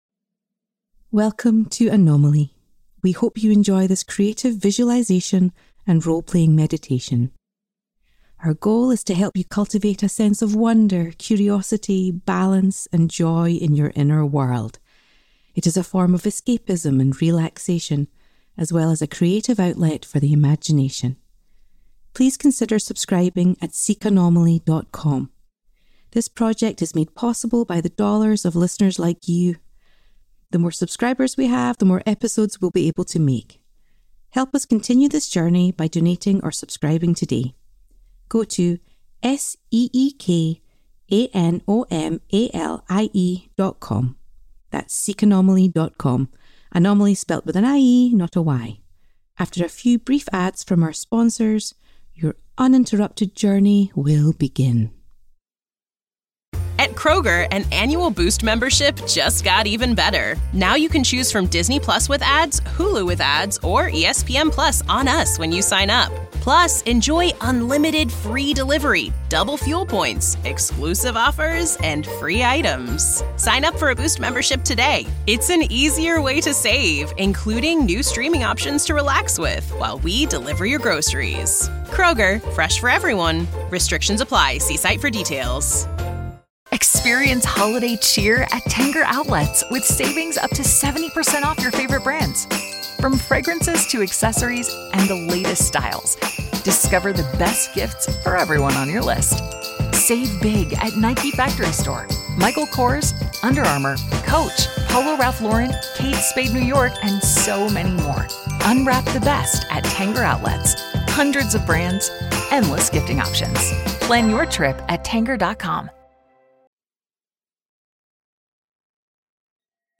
Featured Voice: Ruth Connell